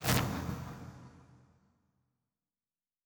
pgs/Assets/Audio/Sci-Fi Sounds/Interface/Complex Interface 2.wav at master
Complex Interface 2.wav